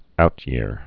(outyîr)